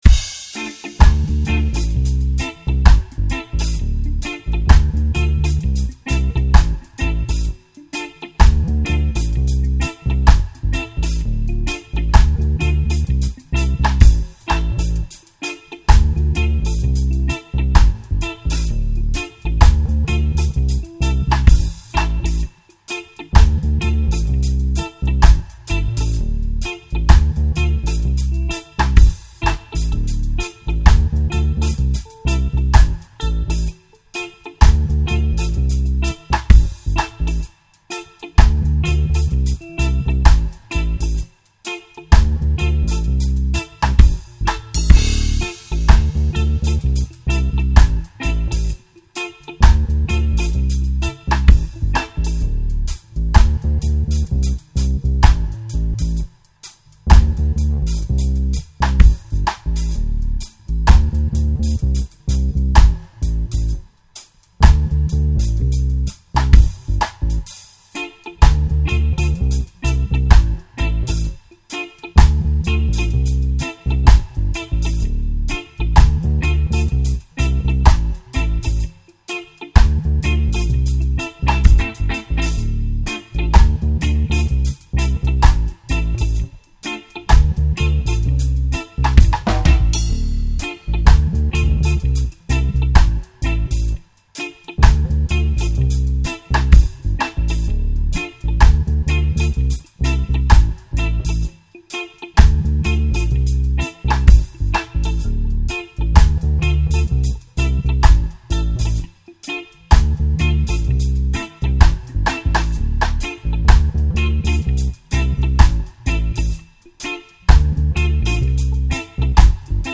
Recorded on the north-side OF TOWN